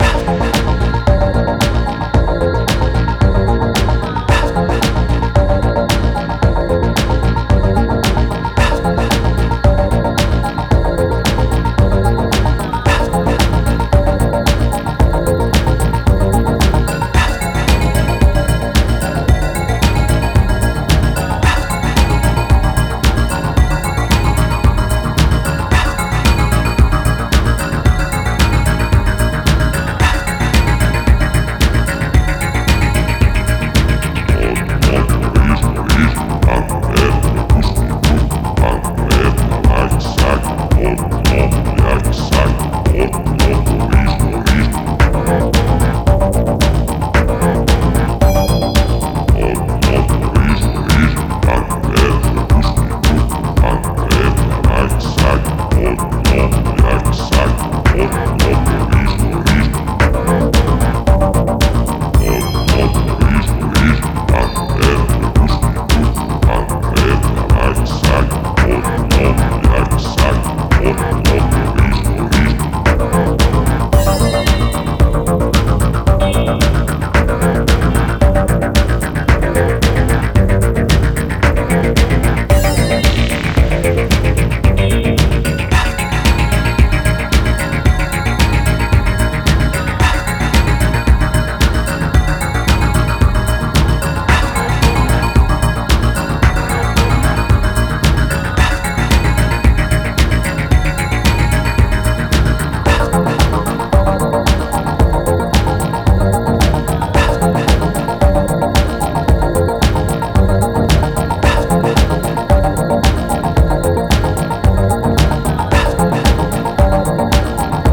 粘度高くコズミック・サウンドのダークサイドを展開するこちらも、現行フロアを席巻するダークなムードにハマります。